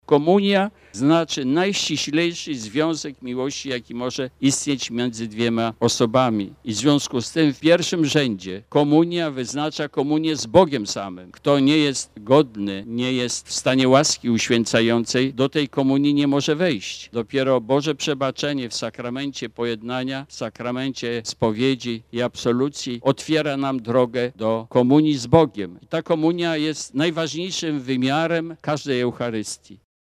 Przy ostatnim ołtarzu, ustawionym przed frontonem świątyni, bp senior diecezji warszawsko-praskiej wygłosił homilię.